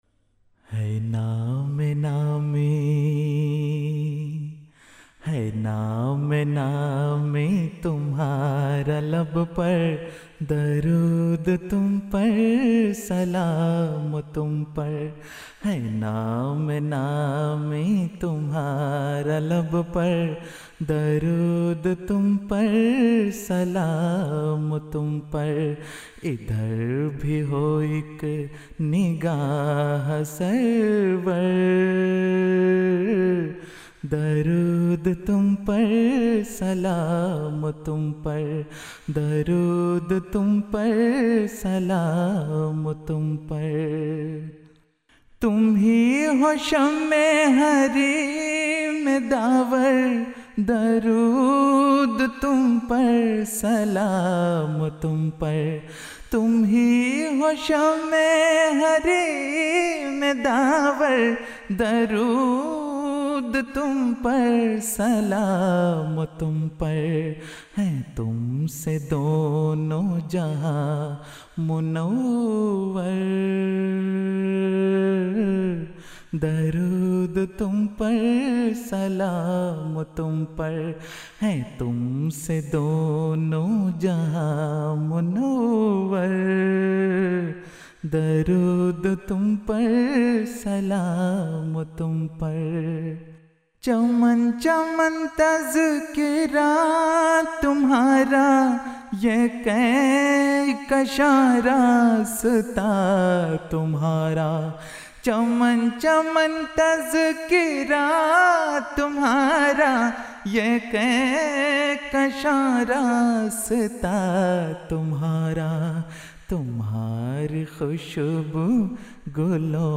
نظمیں (Urdu Poems)
جلسہ سالانہ ربوہ ١٩٨٢ء Jalsa Salana Rabwah 1982